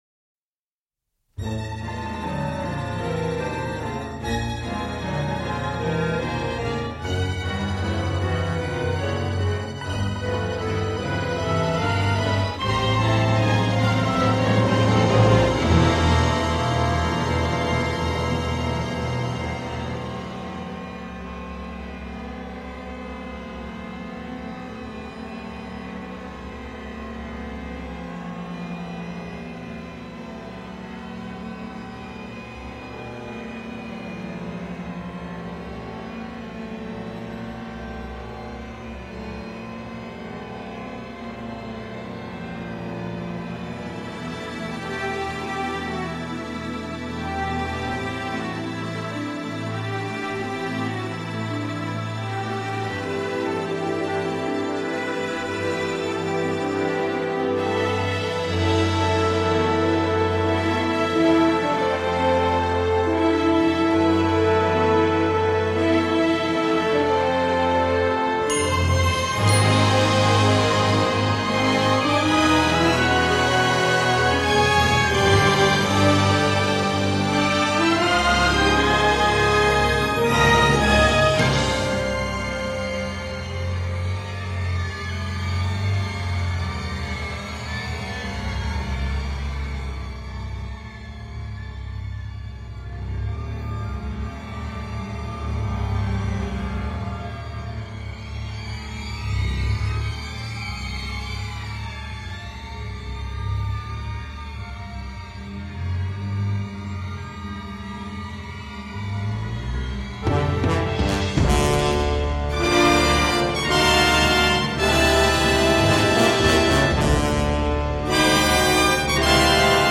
Remplie d’effets étranges, déroutants, et d’émotion brute.
Ici, on est là pour flipper et être mal à l’aise.